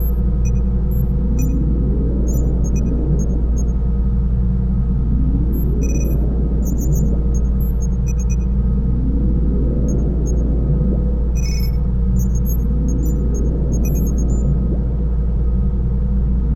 weaponroom.ogg